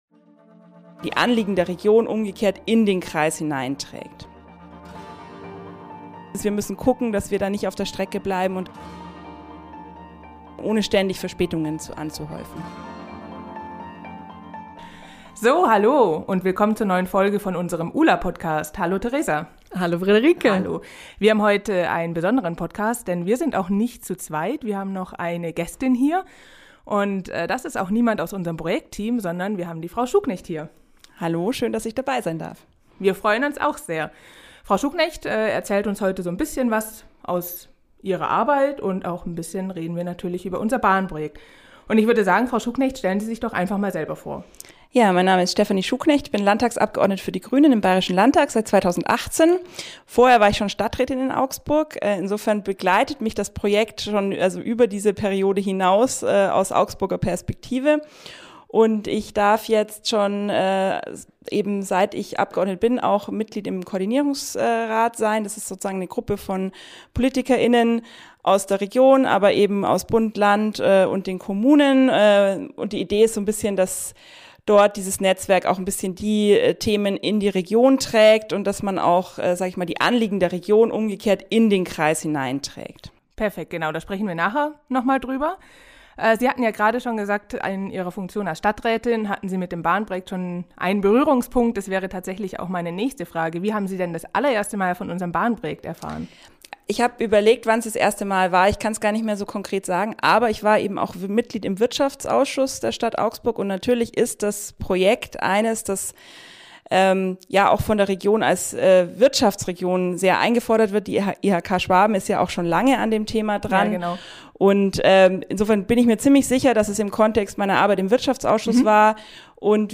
Ein Gespräch mit Stephanie Schuhknecht über langwierige Bahnprojekte, Überholvorgänge und ihre Rolle als Landespolitikerin in einem Bundesprojekt.